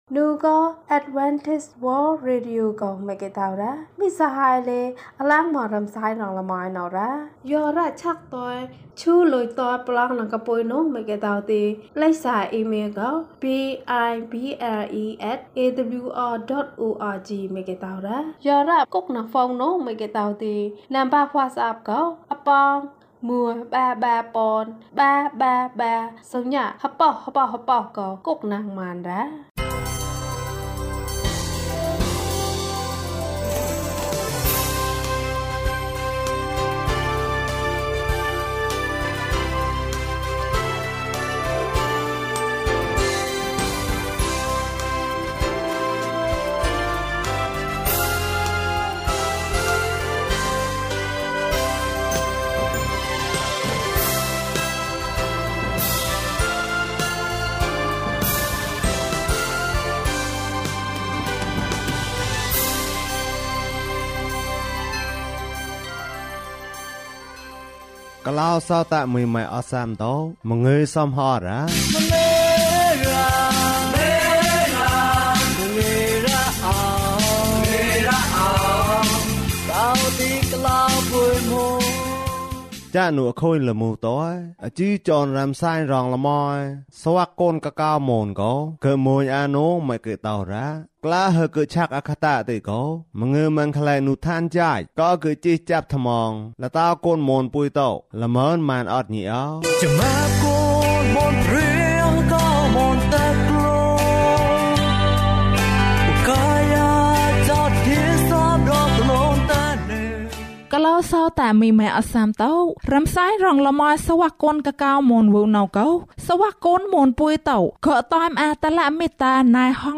ကောန်မၞးအိုတ်သီုတံဂှ်မၞးလဴထၐးကဵုပရောသၠပတ်သမ္မာညိ။ ဓမ္မသီချင်း။ တရားဒေသနာ။